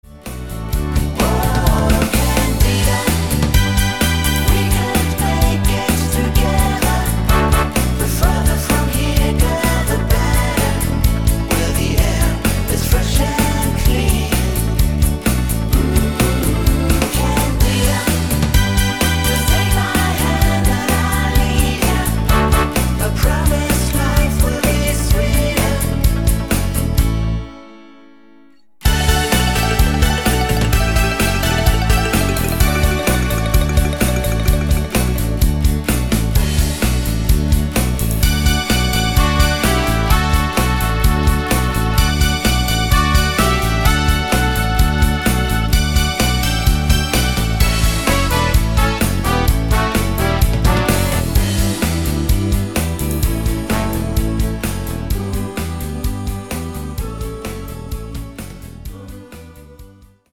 Rhythmus  Beat Cha cha